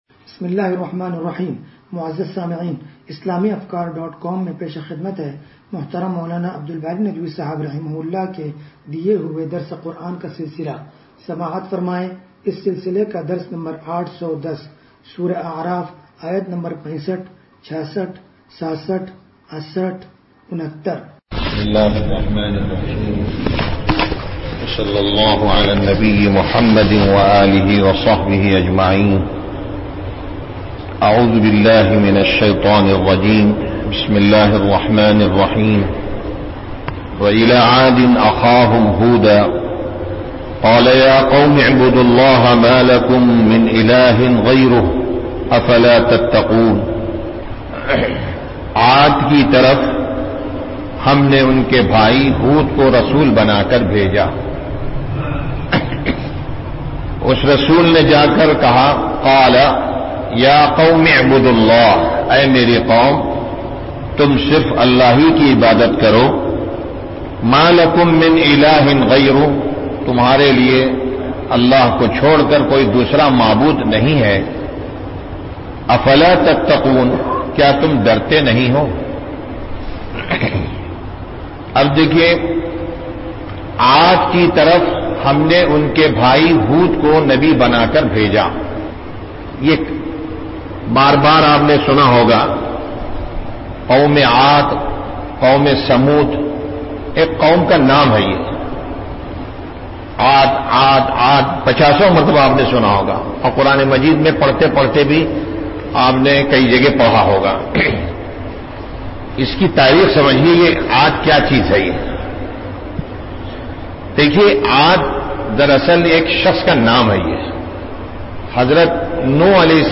درس قرآن نمبر 0810
درس-قرآن-نمبر-0810.mp3